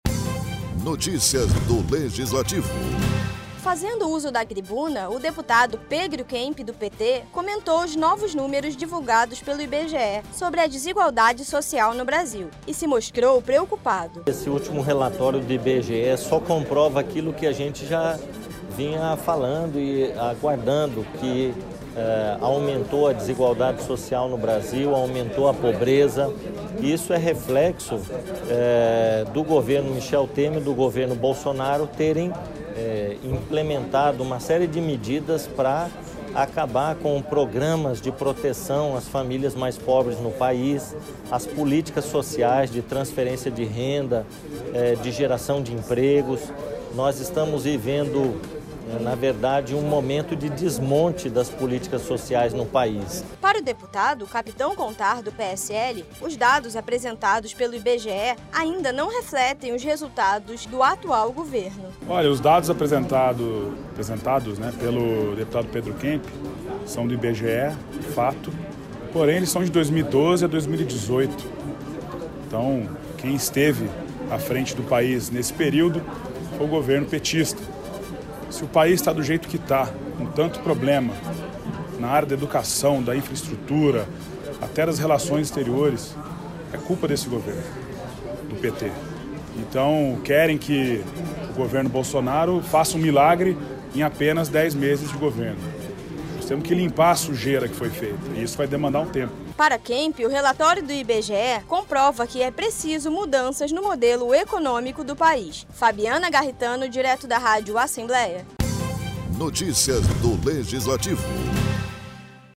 O deputado Pedro Kemp, do PT usou a tribuna para comentar os números divulgados pelo Instituto Brasileiro de Geografia e Estatística (IBGE), que apresentam um aumento nos índices da desigualdade social no País.